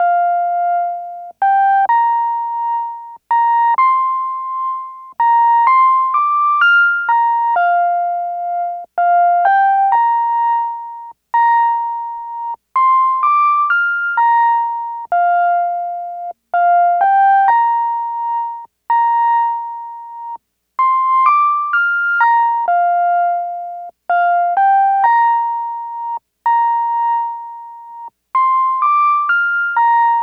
Track 10 - Piano Lead.wav